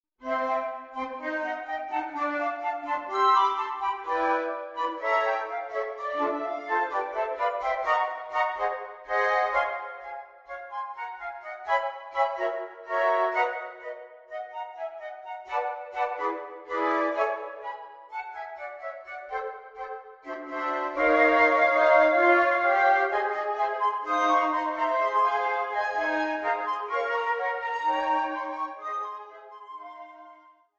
This is a fun suite of four Latin-American dances.